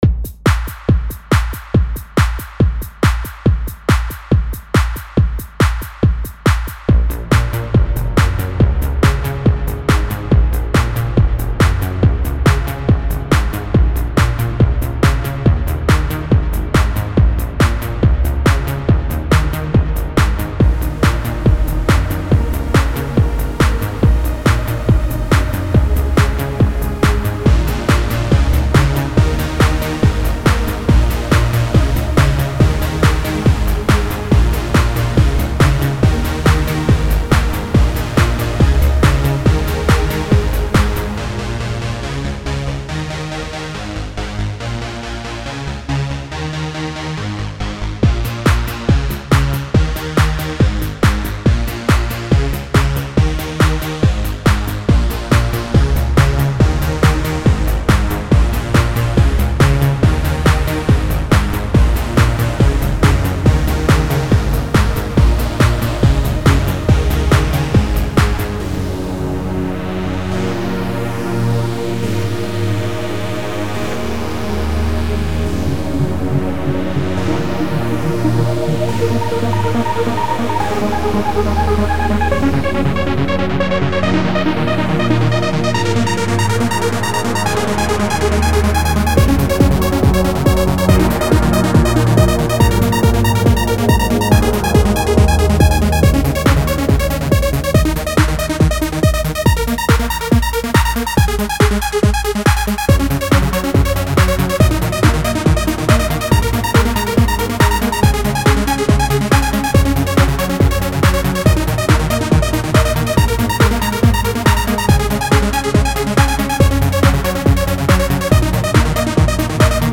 Not really too fond of trance...